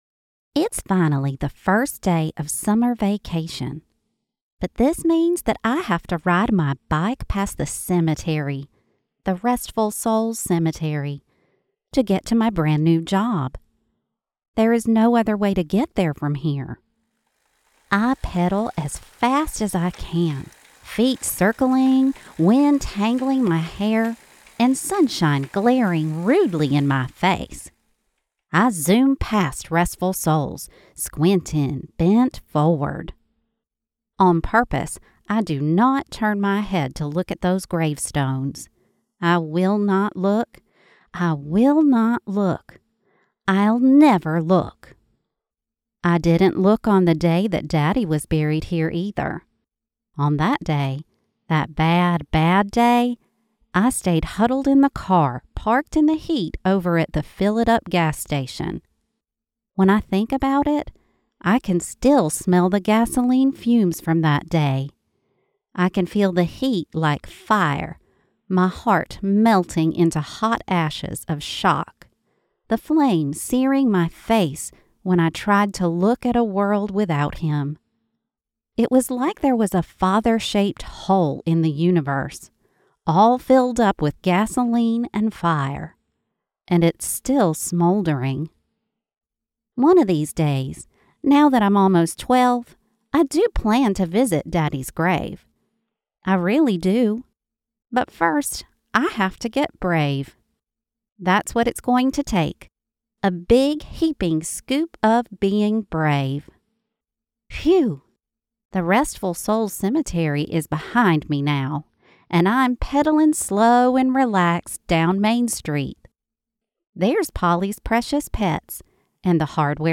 First There Was Bird Audiobook
Audiobook